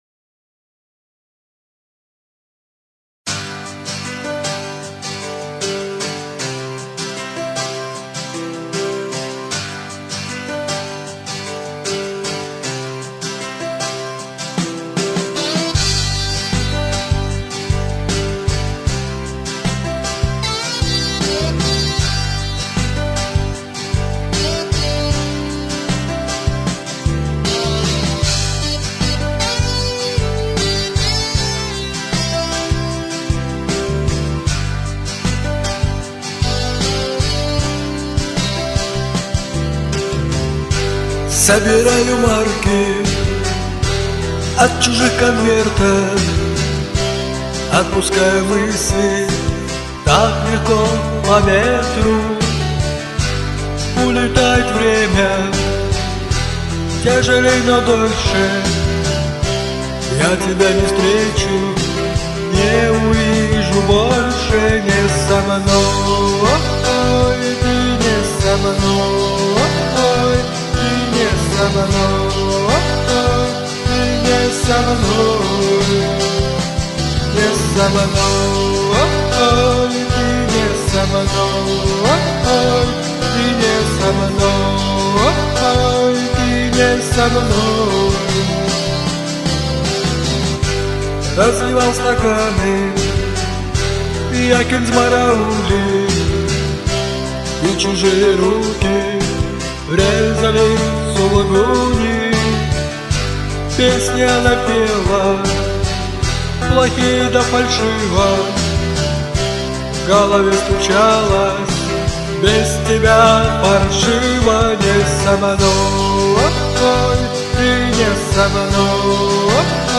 Какой он у тебя лиричный получился...